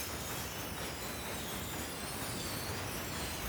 BackSound0701.wav